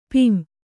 ♪ pim/pin